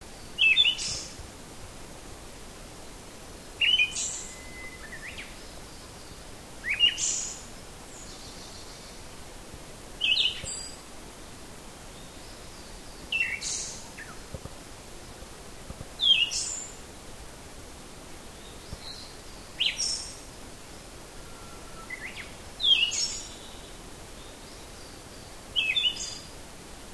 【場　所】　岡山北東部
【環　境】　低山
ツグミの仲間らしい独特の声だった。
マミジロの声（188KB）